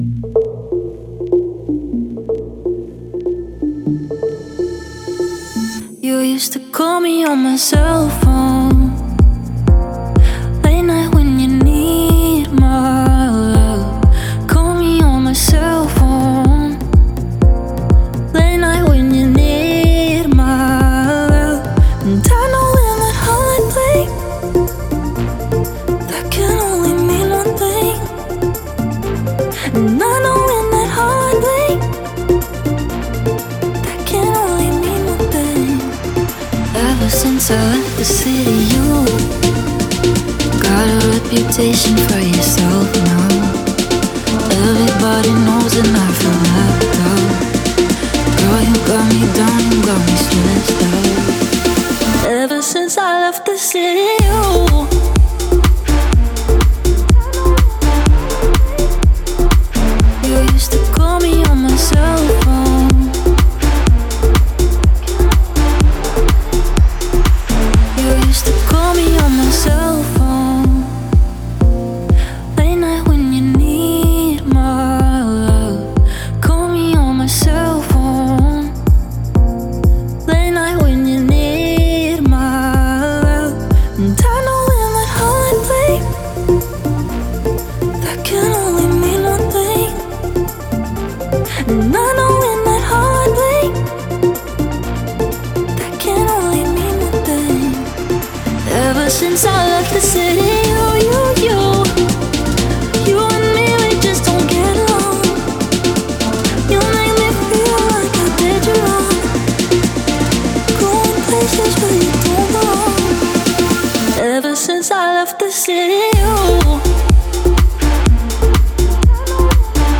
это яркий трек в жанре хип-хоп с элементами R&B